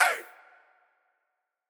SouthSide Chant (40).wav